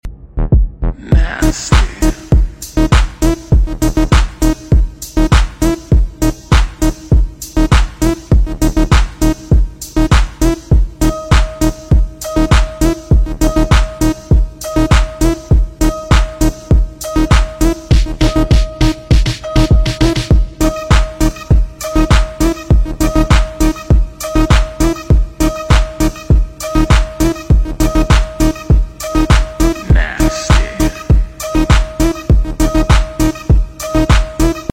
Xsuv 250 pov şürüş sound effects free download